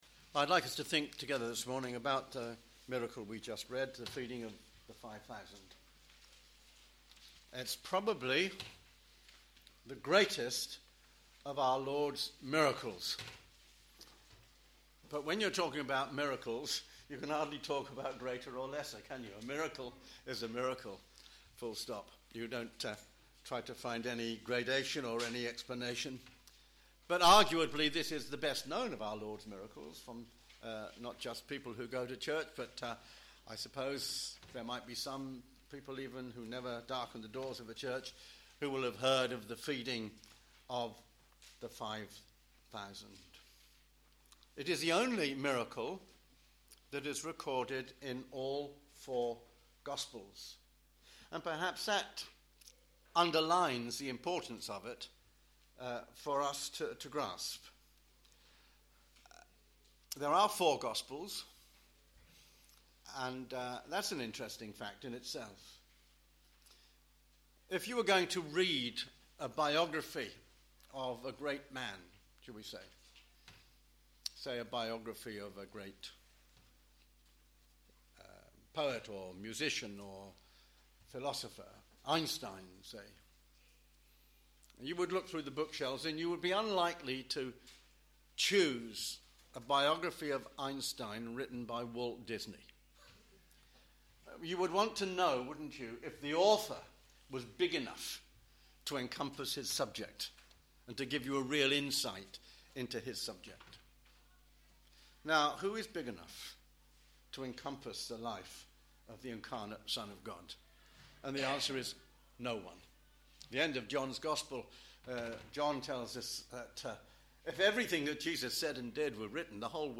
Media for a.m. Service on Sun 24th Feb 2013 10:30
Theme: Bread of Life Sermon